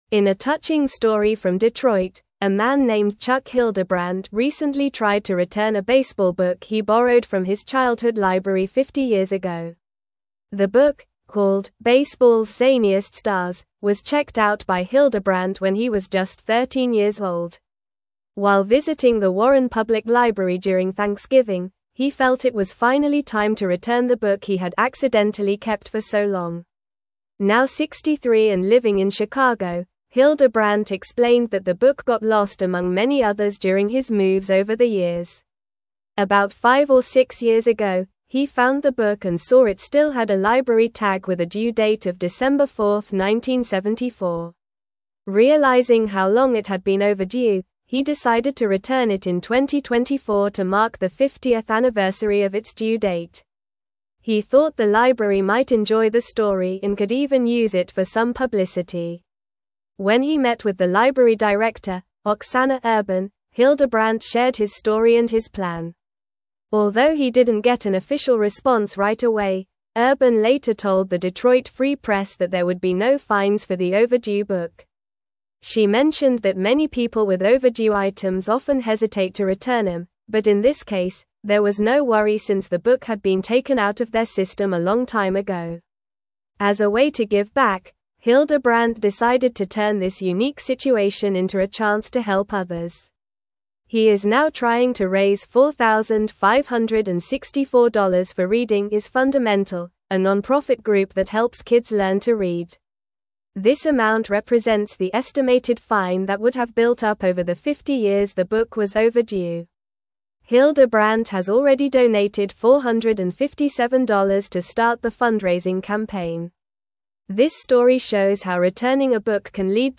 Normal
English-Newsroom-Upper-Intermediate-NORMAL-Reading-Man-Returns-Overdue-Library-Book-After-50-Years.mp3